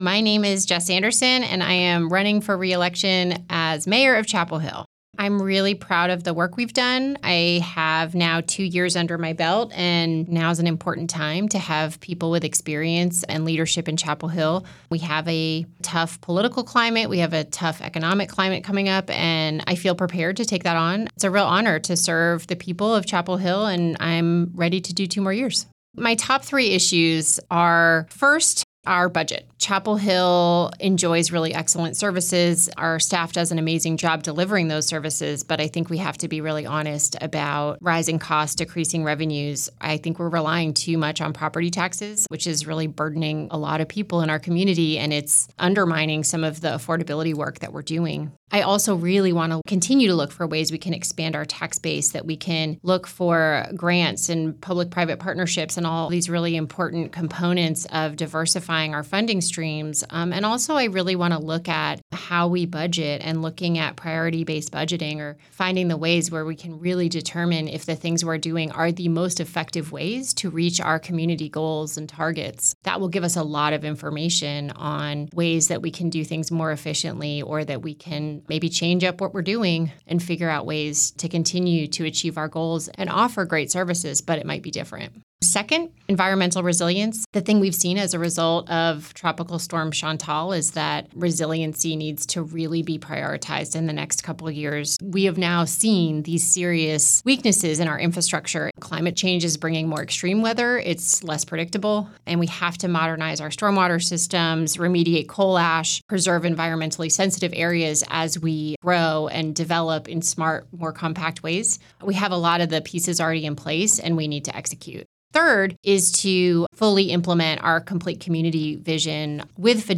97.9 The Hill spoke with each candidate, asking these questions that are reflected in the recorded responses: